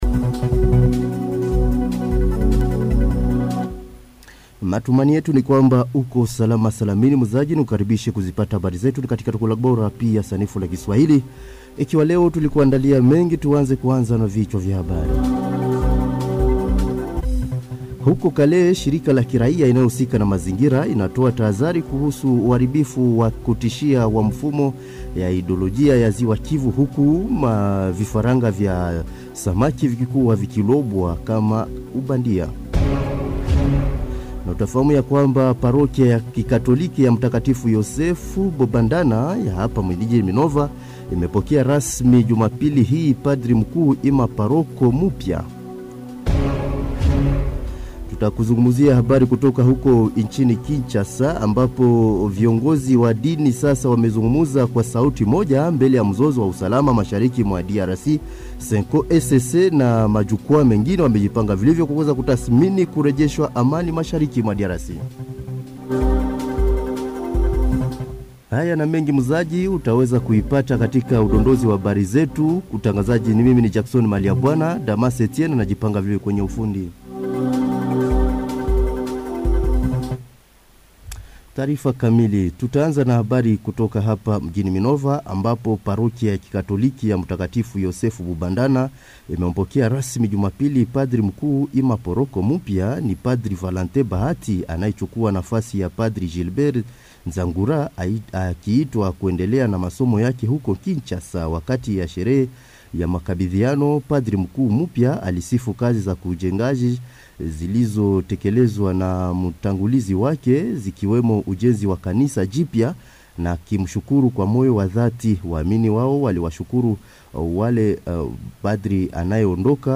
Journal